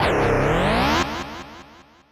snd_cardrive.wav